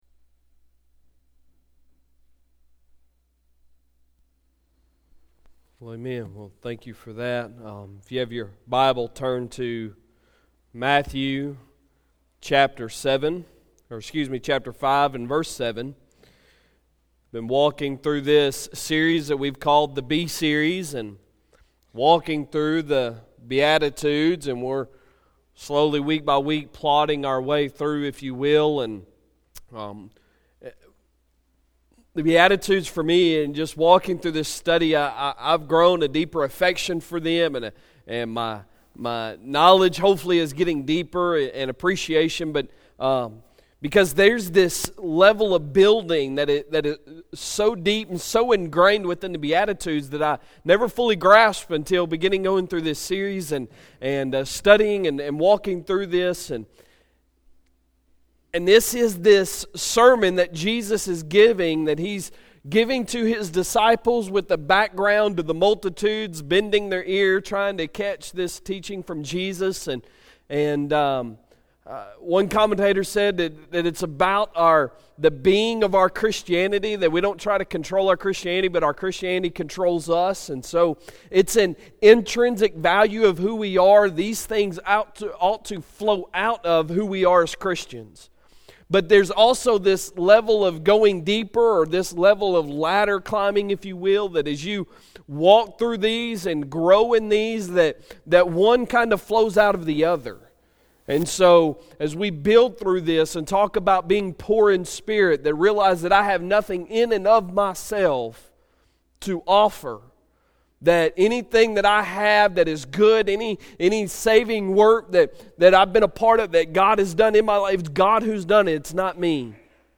Sunday Sermon October 14, 2018